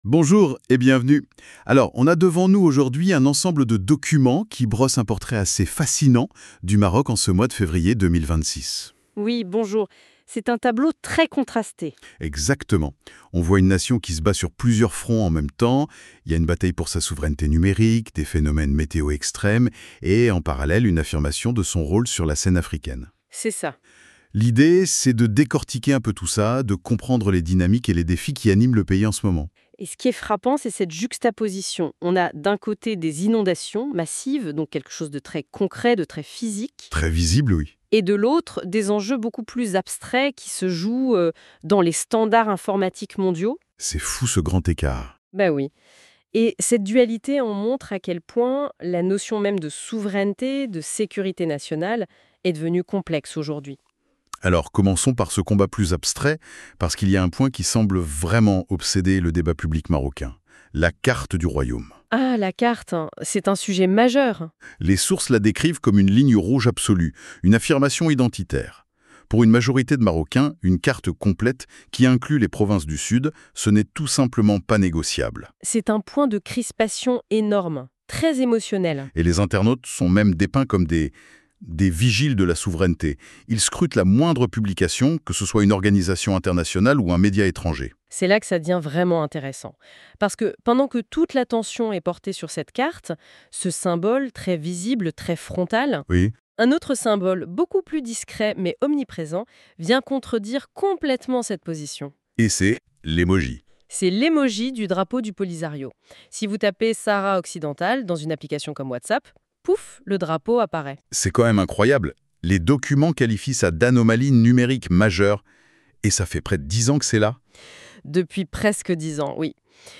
Les débats en podcast des chroniqueurs de la Web Radio R212 débattent de différents sujets d'actualité